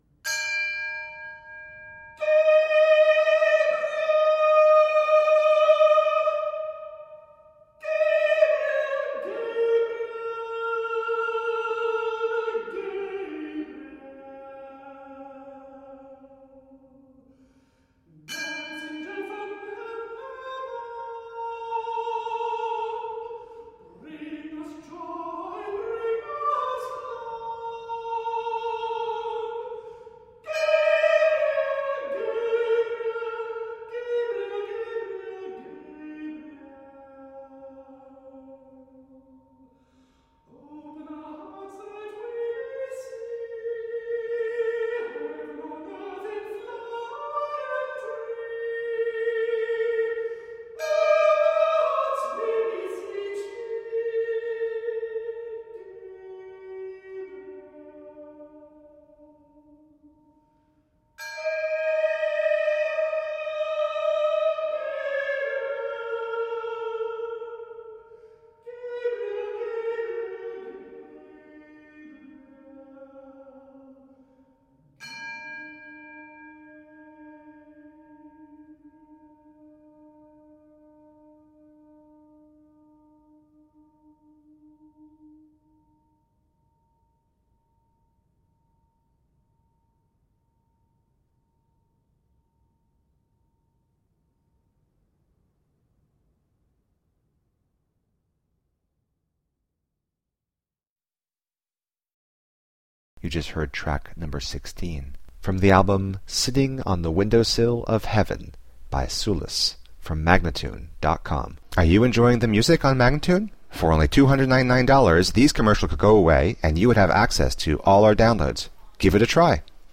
Heavenly, healing, early music.